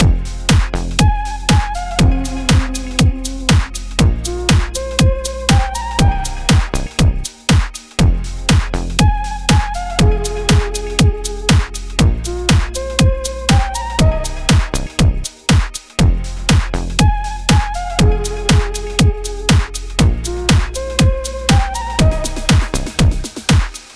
F Minor
Poppin Low
Plucky Hop
Saw Memories